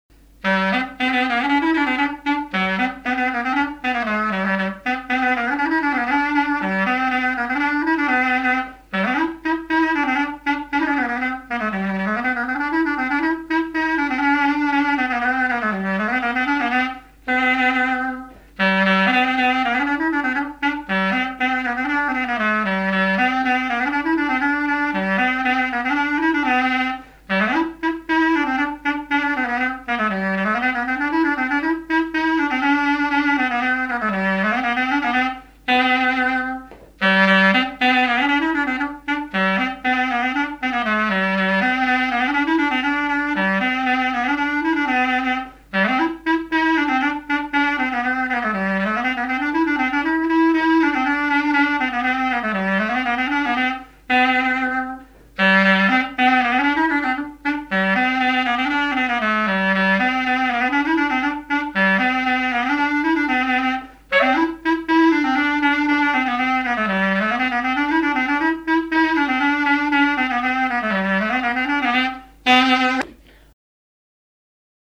Mémoires et Patrimoines vivants - RaddO est une base de données d'archives iconographiques et sonores.
danse : branle : avant-deux
Catégorie Pièce musicale inédite